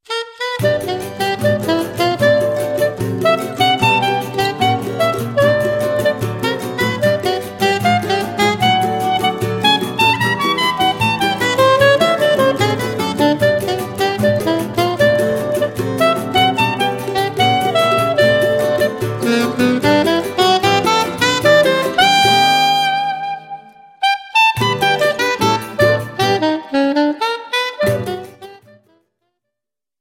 soprano saxophone
Choro ensemble